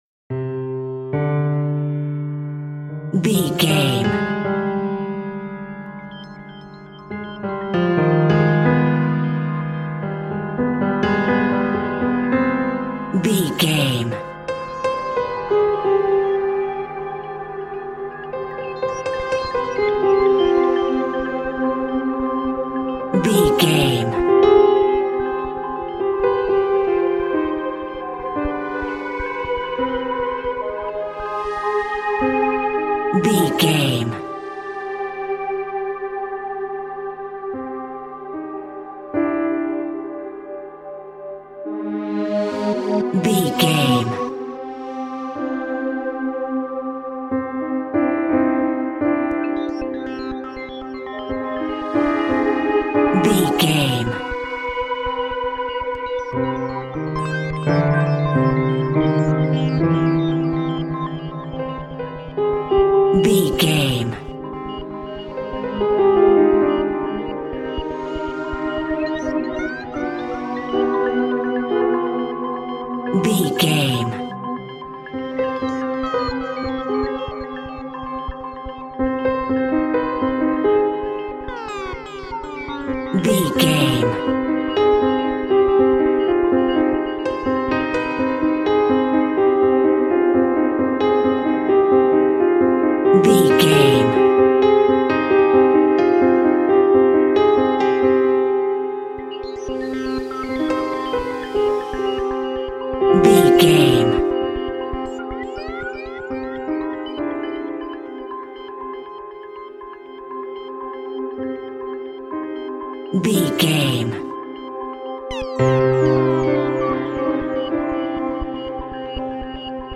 Aeolian/Minor
Slow
scary
ominous
dark
suspense
eerie
piano
synthesiser
horror
ambience
pads